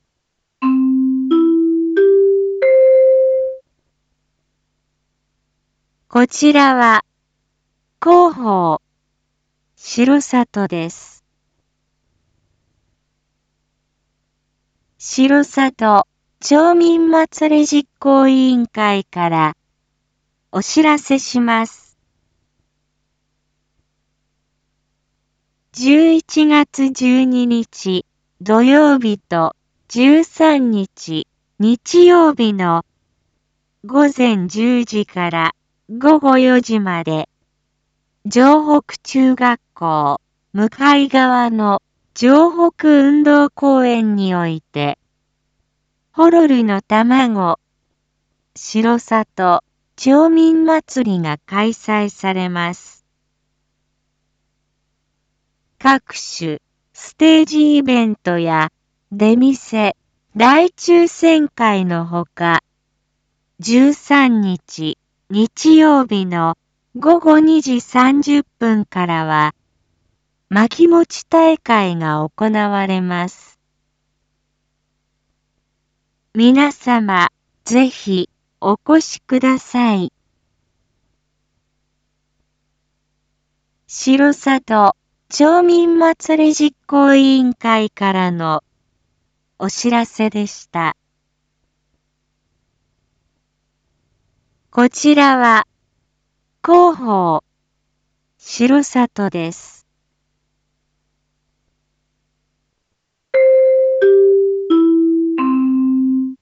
一般放送情報
Back Home 一般放送情報 音声放送 再生 一般放送情報 登録日時：2022-11-12 07:01:48 タイトル：R4.11.12 7時放送分 インフォメーション：こちらは、広報しろさとです。